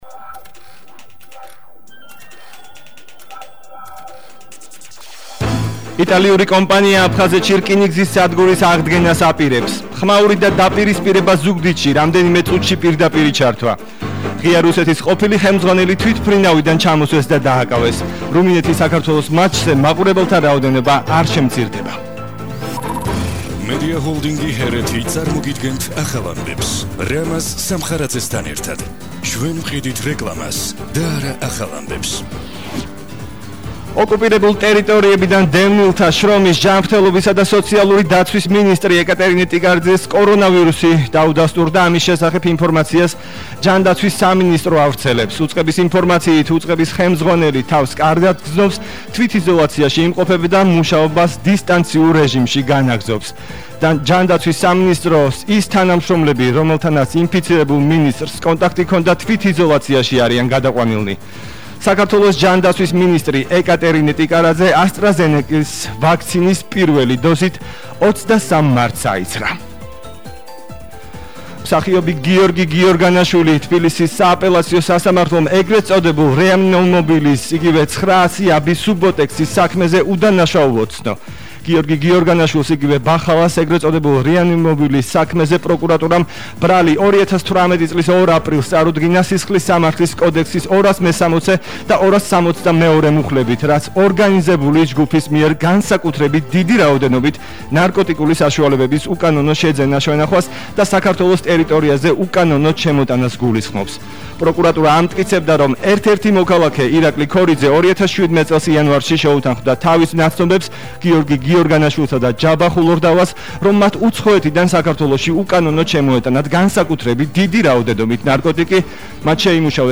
ახალი ამბები 14:00 საათზე –01/06/21 – HeretiFM
🟠ხმაური და დაპირისპირება ზუგდიდში – რამდენიმე წუთში პირდაპირი ჩართვა